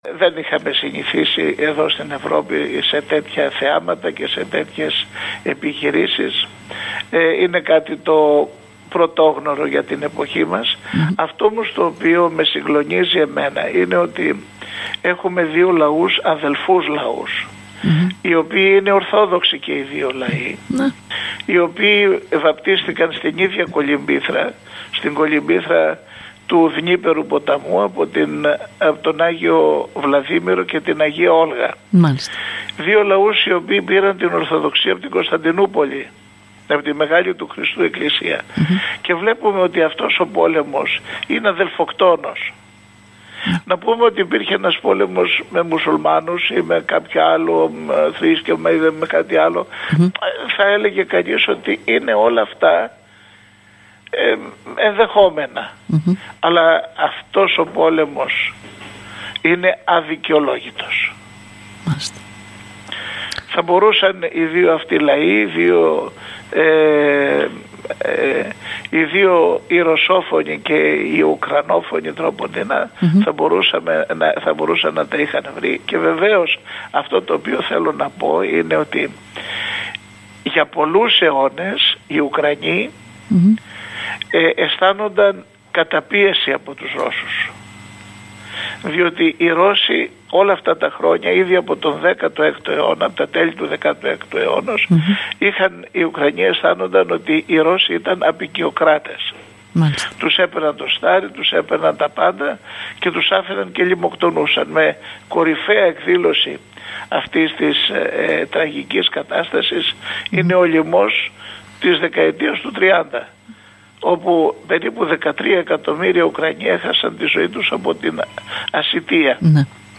«Δεν είχαμε συνηθίσει εδώ στην Ευρώπη σε τέτοια θεάματα και σε τέτοιες επιχειρήσεις. Είναι κάτι το πρωτόγνωρο για την εποχή μας. Αυτό όμως το οποίο με συγκλονίζει εμένα είναι ότι έχουμε δύο λαούς, αδελφούς λαούς, οι οποίοι είναι Ορθόδοξοι και οι δύο λαοί, οι οποίοι βαπτίστηκαν στην ίδια κολυμπήθρα, στην κολυμπήθρα του Δνείπερου ποταμού από τον Άγιο Βλαδίμηρο και την Αγία Όλγα – δύο λαούς, οι οποίοι πήραν την Ορθοδοξία από την Κωνσταντινούπολη, από την Μεγάλη του Χριστού Εκκλησία και βλέπουμε ότι αυτός ο πόλεμος είναι αδελφοκτόνος», δήλωσε, μεταξύ άλλων, σε εκπομπή της ΕΡΤ Ορεστιάδας.